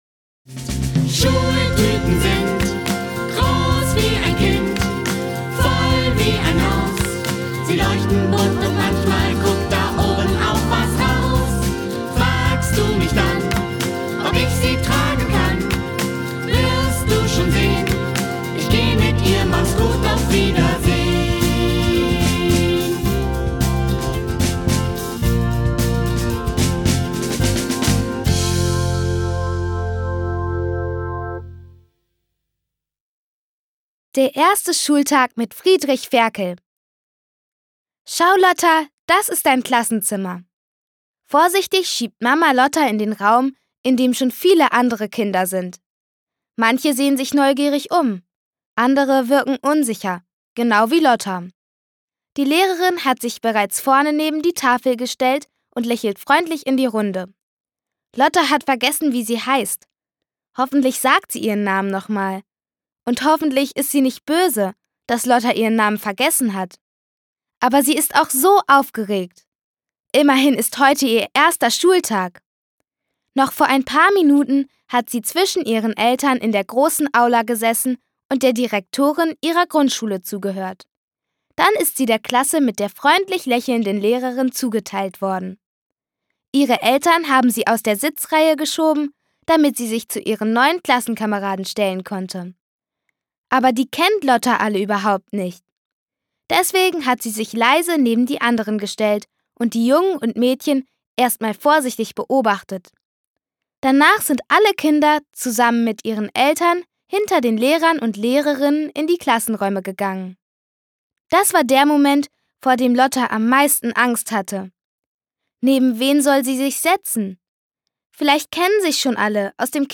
Geschichten, Reime und Lieder